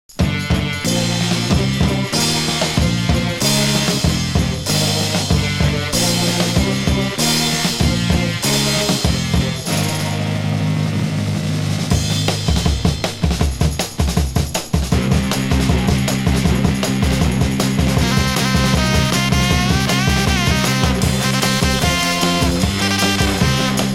ジャケのデザインにも反映されている様に時代のトレンドのサイケも感じるカラフルな1枚。
(税込￥3080)   PSYCH